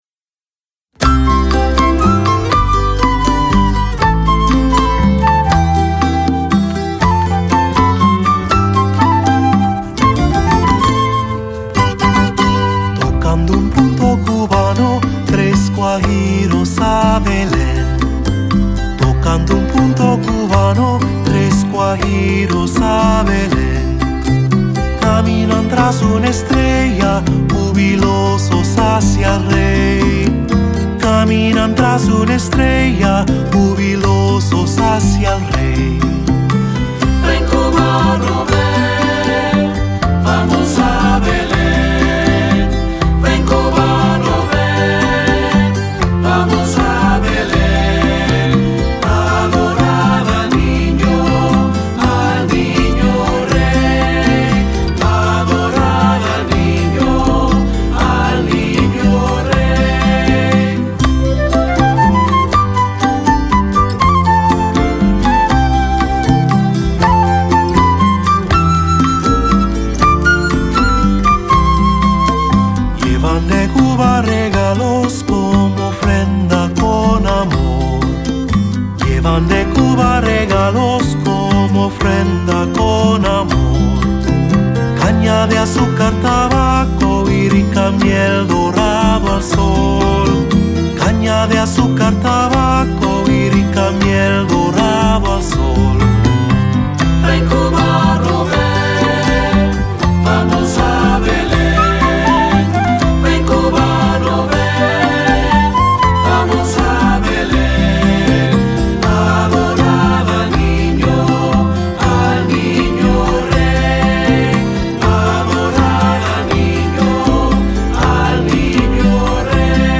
Voicing: Three-part; Cantor; Assembly